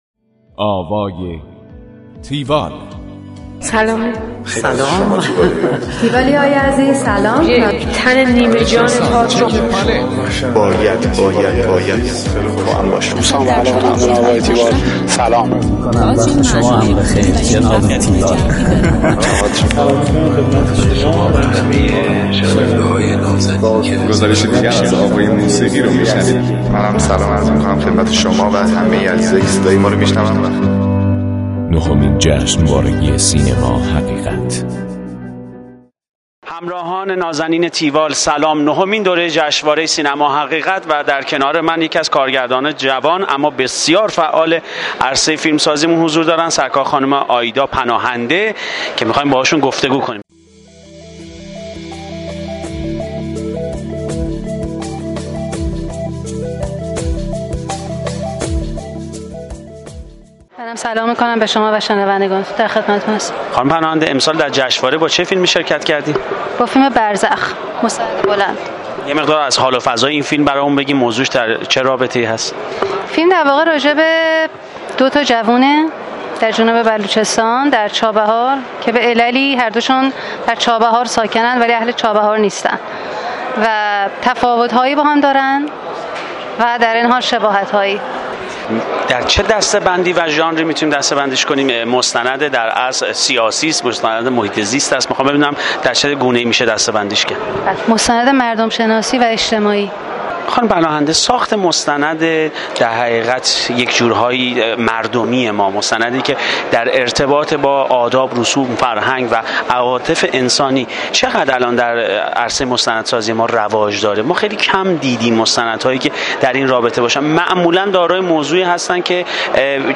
گفتگو کننده
گفتگوی تیوال